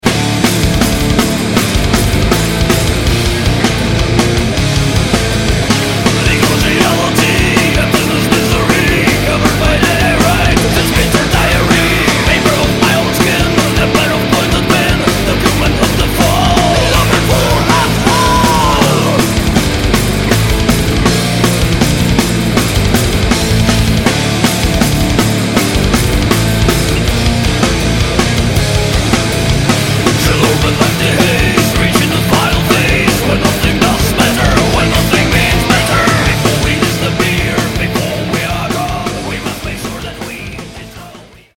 Nahrávací studio v Lipově audio / digital
Trimetalová kapela